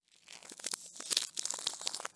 Cloack Disabled Sound.wav